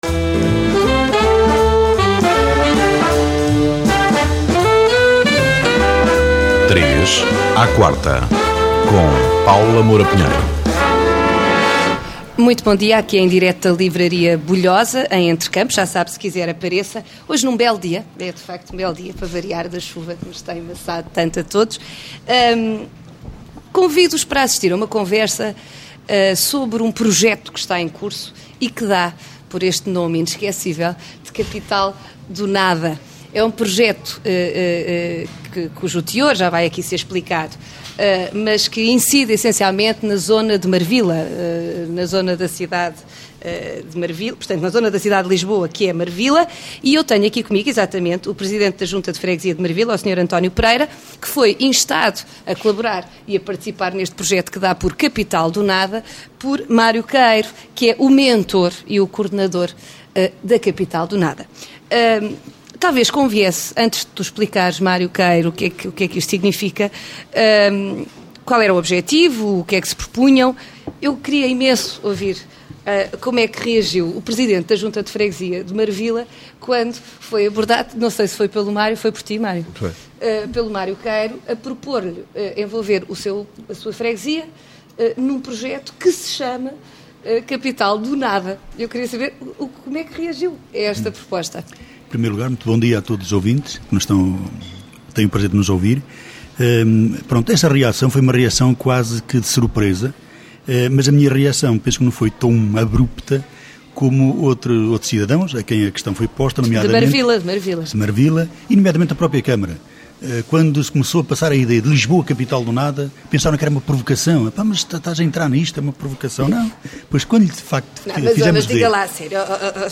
Entrevista com Paula Moura Pinheiro